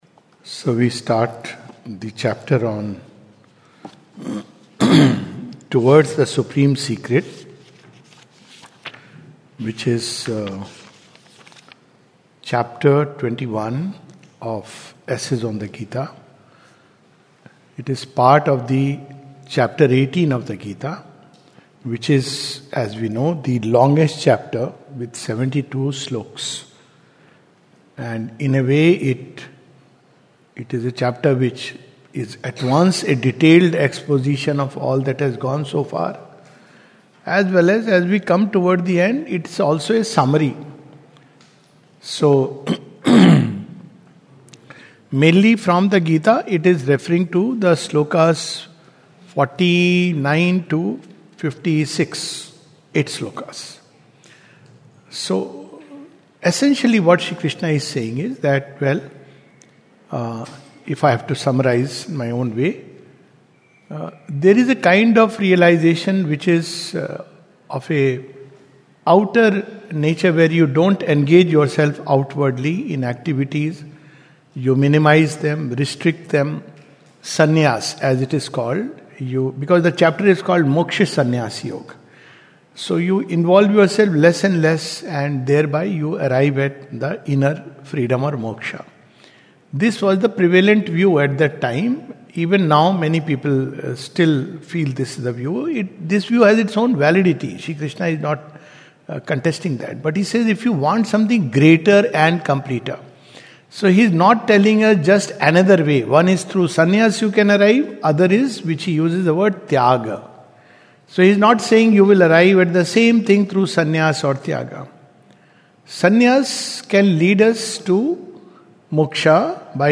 This is a summary of the first part of Chapter 21 of the Second Series of "Essays on the Gita" by Sri Aurobindo. A talk